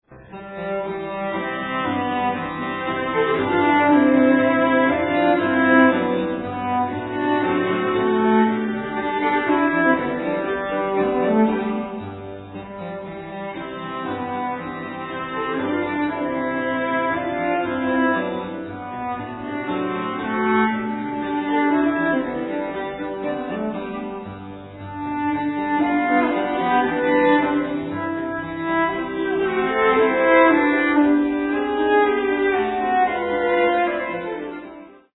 harpsichord
violin
cello